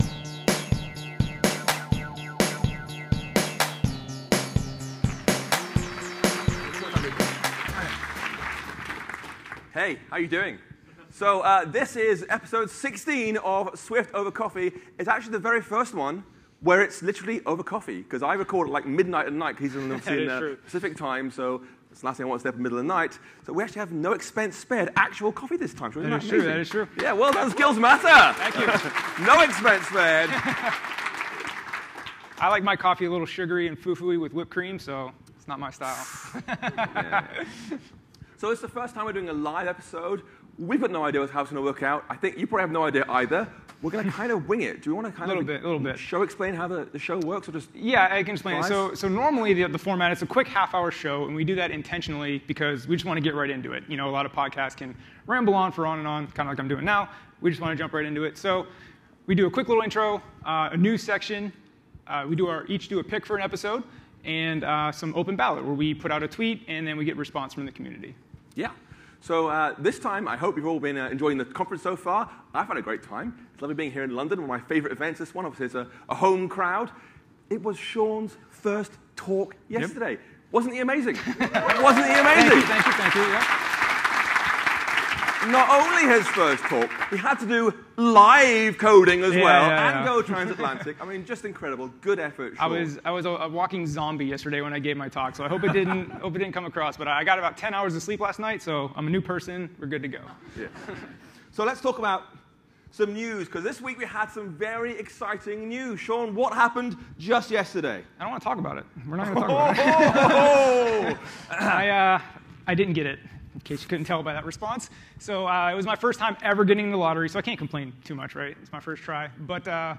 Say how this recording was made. S1E16: Live from iOSCon 2019 by Swift over Coffee